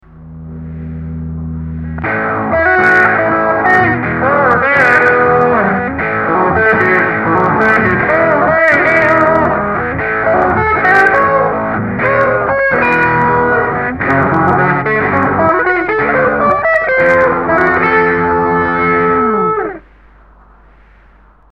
Blues-Rock